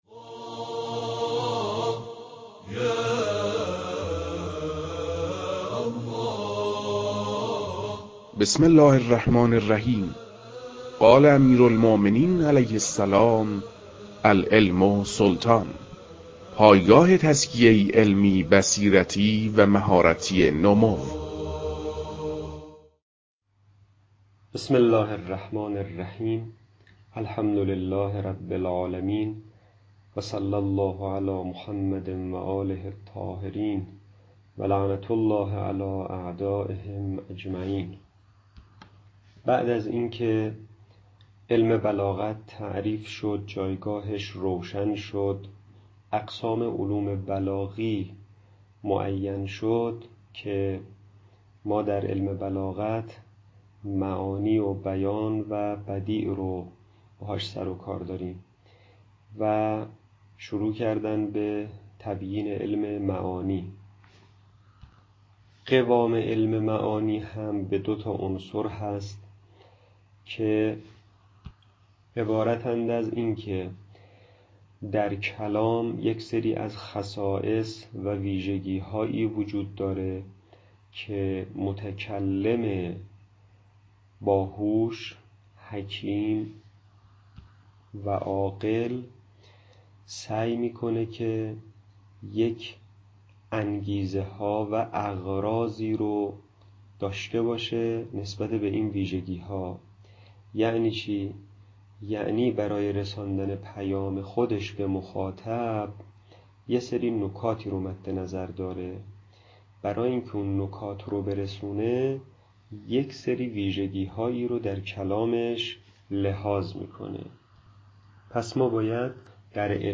در این بخش، کتاب «لمحات من البلاغة» که اولین کتاب در مرحلۀ آشنایی با علم بلاغت است، به صورت ترتیب مباحث کتاب، تدریس می‌شود.